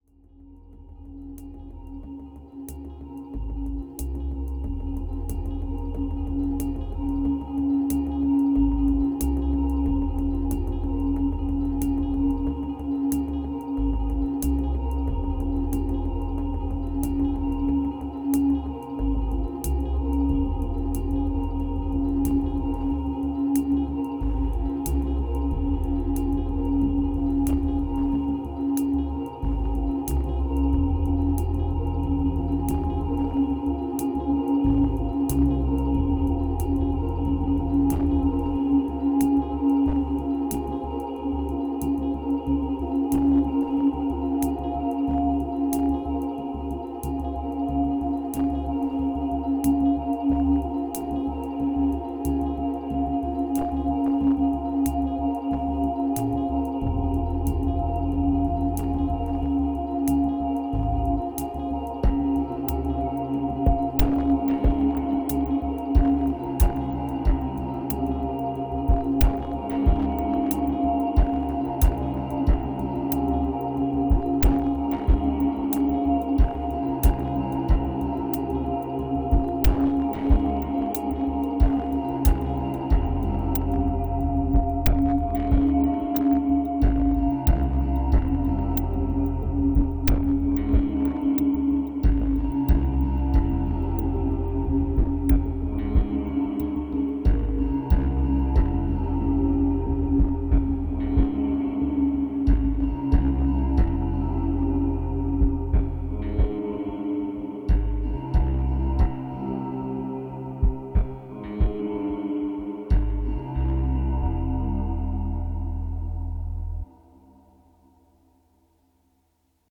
(interlude)
2379📈 - 77%🤔 - 92BPM🔊 - 2017-06-05📅 - 724🌟